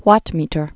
(wŏtmētər)